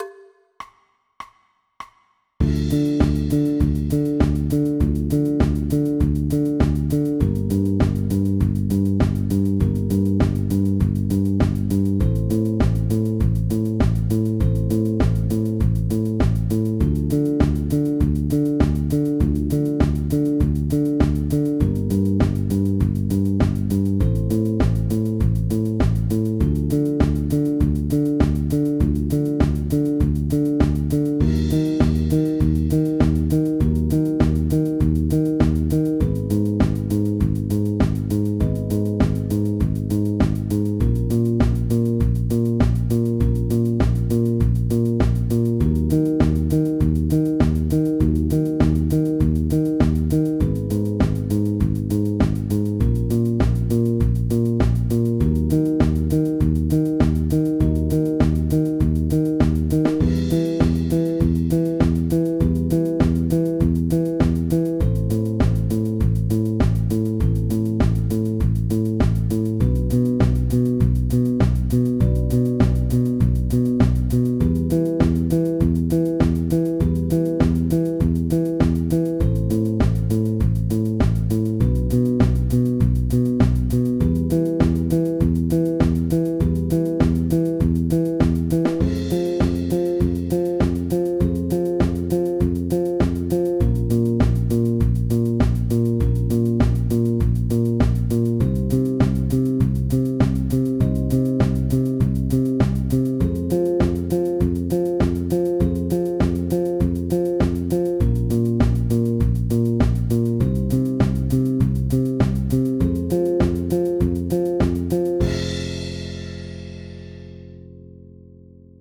Download Zeg Maor Moi (instrumentaal modulaties Dm, Ebm, Em, Fm)